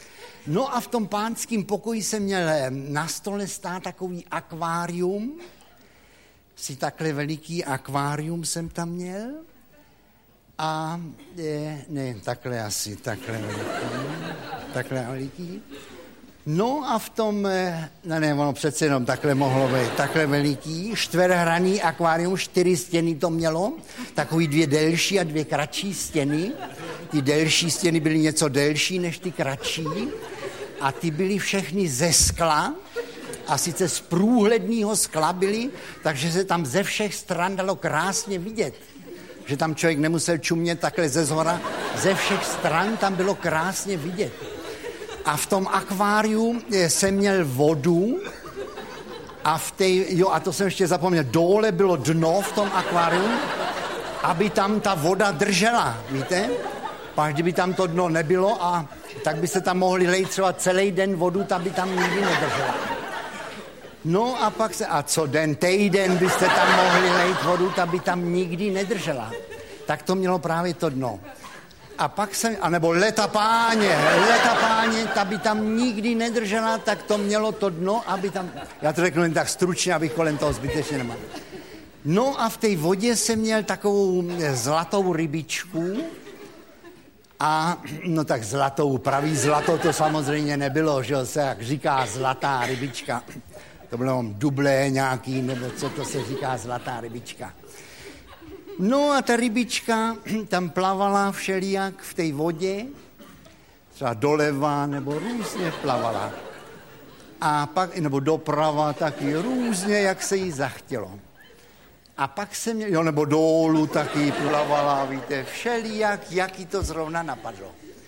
Ještě 10x Felix Holzmann audiokniha
Deset scének populárního komika, který byl v uplynulých letech ozdobou každého televizního Silvestra, navazuje na mimořádně úspěšný titul Supraphonu 10x Felix Holzmann